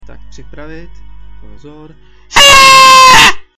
trumpeta.mp3